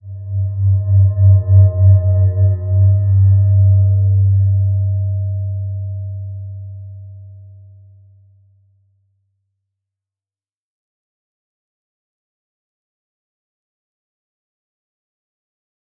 Slow-Distant-Chime-G2-mf.wav